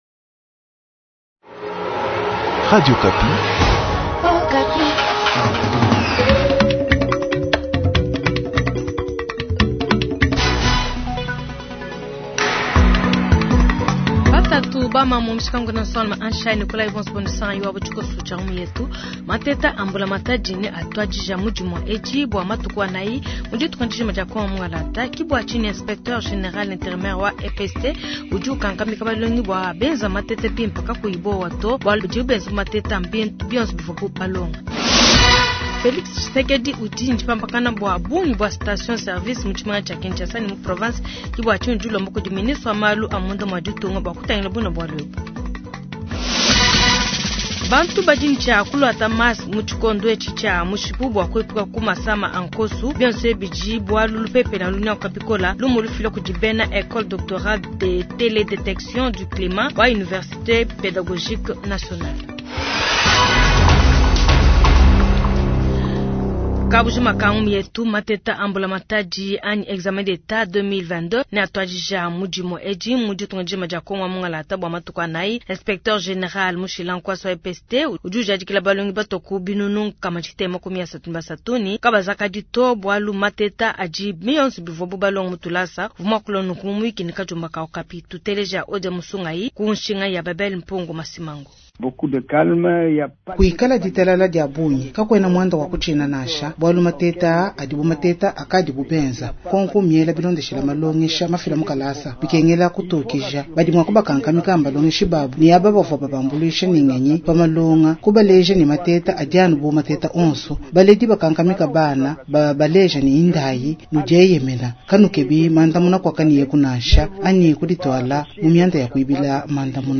Journal du Lundi 180722